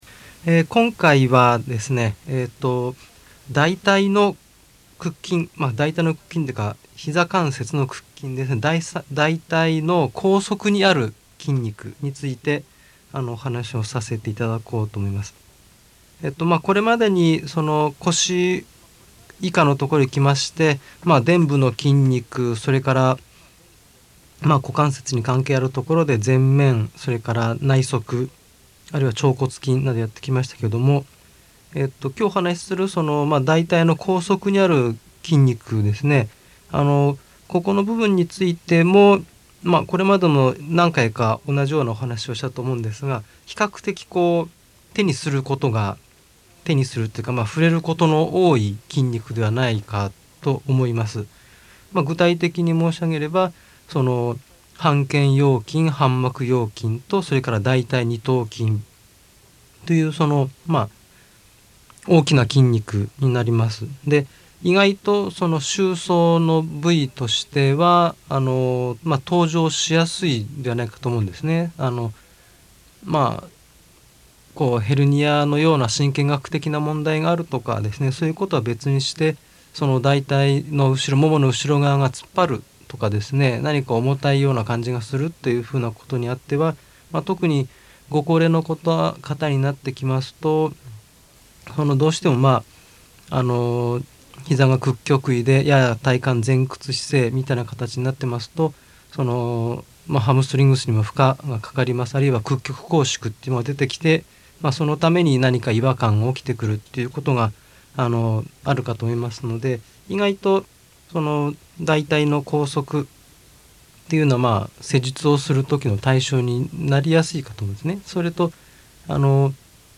（一部テープの状態が悪かったため、お聞き苦しい点があるかも知れません。）